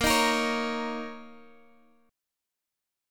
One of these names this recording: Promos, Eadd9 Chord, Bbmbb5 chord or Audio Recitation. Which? Bbmbb5 chord